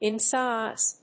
/ɪnsάɪz(米国英語)/